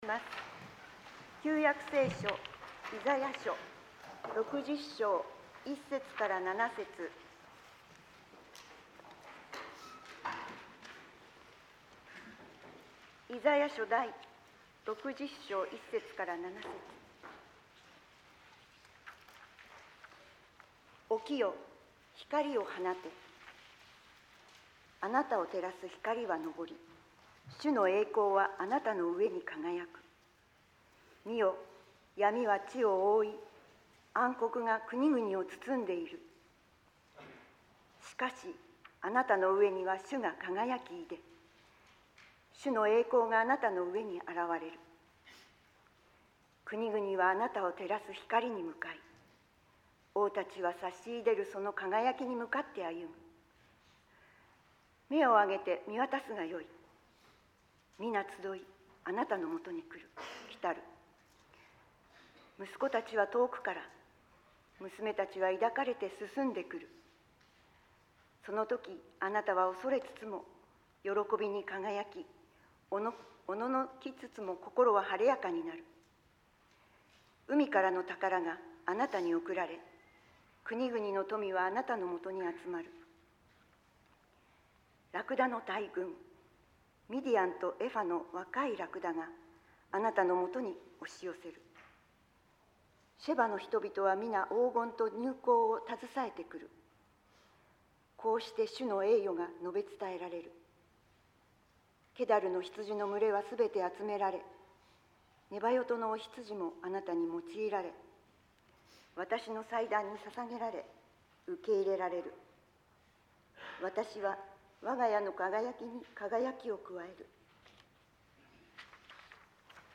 説教